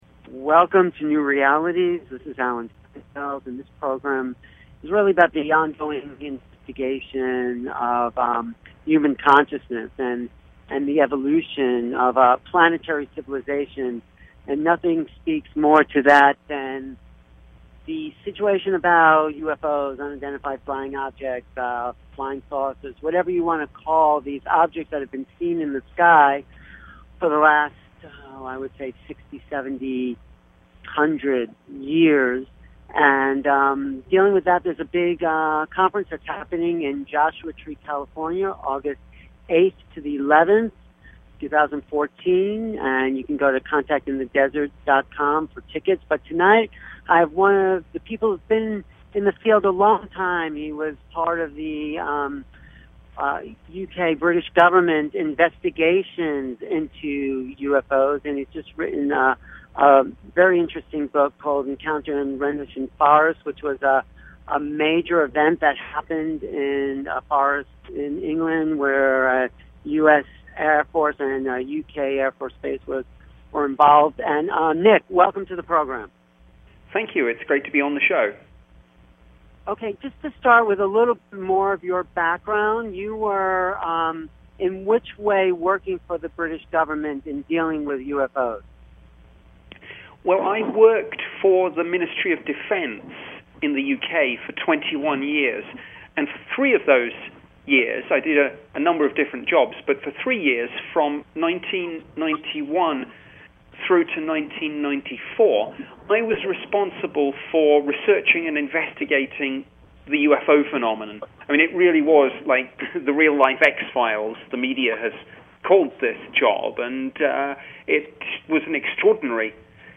Guest, Nick Pope